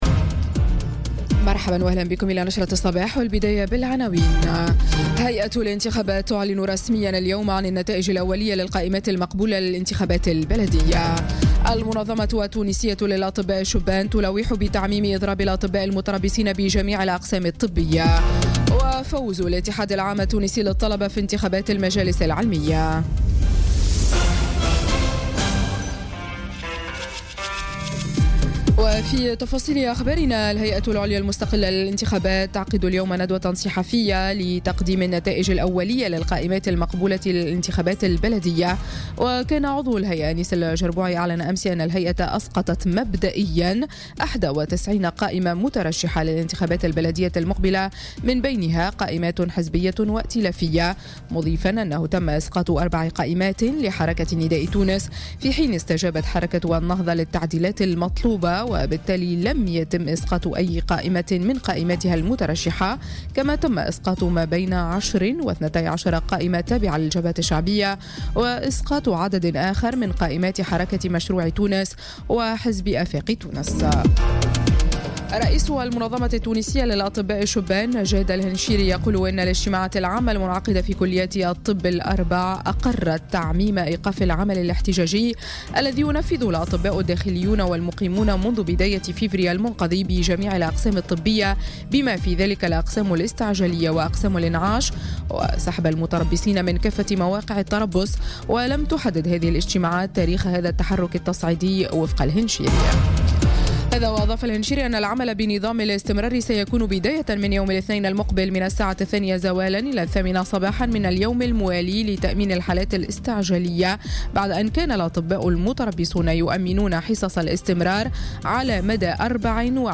نشرة أخبار السابعة صباحا ليوم السبت 3 مارس 2018